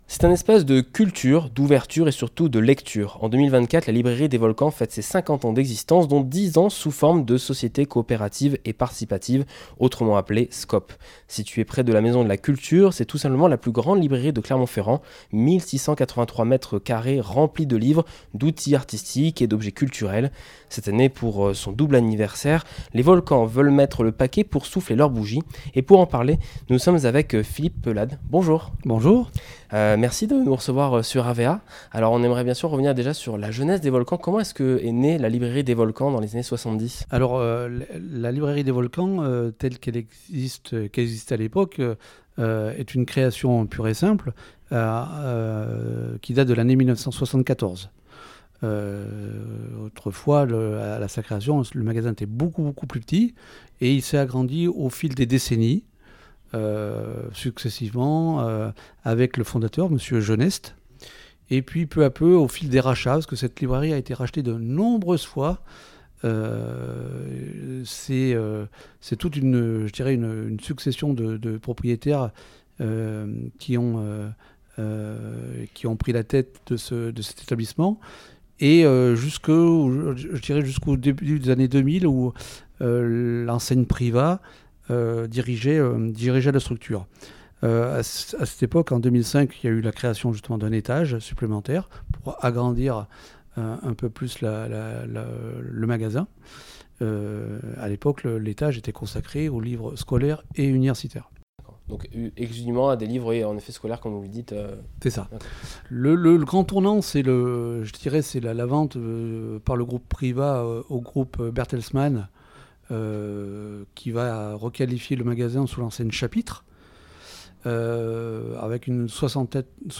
La librairie Les Volcans fête cette année ses 10 ans avec un week-end de festivité pour discuter et débattre avec des auteurs. Discussion